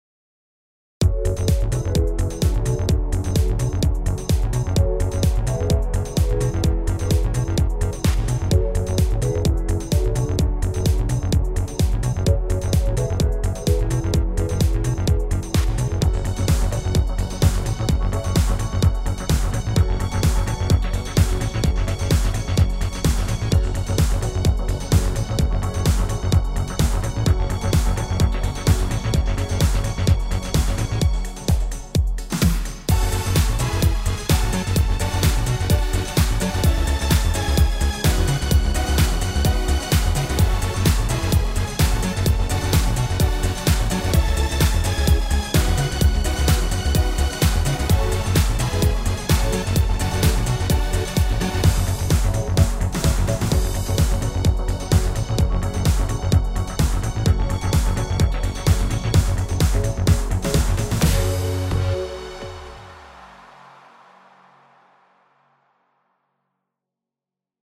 Uptempo Shuffle mit spannendem Grundbeat.